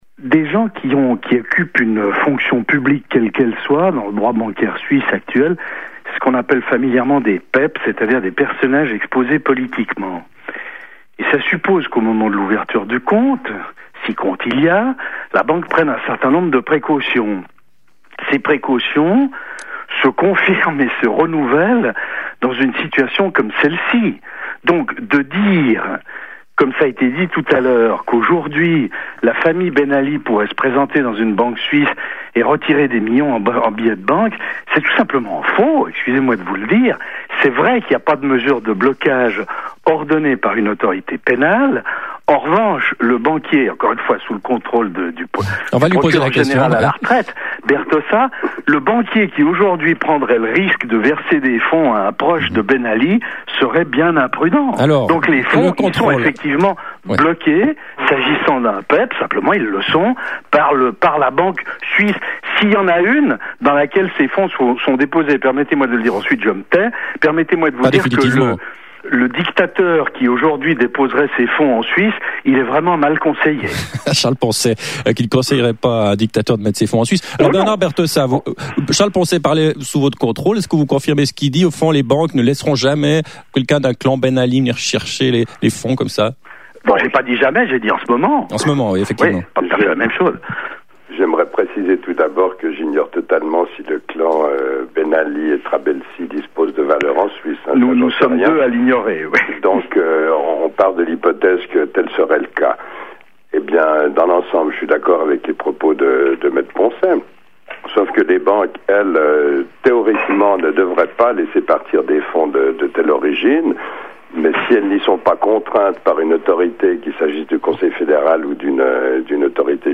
Charles Poncet & Bernard Bertossa : débat sur les fonds Ben Ali
Charles Poncet, avocat, et Bernard Bertossa, ancien procureur de Genève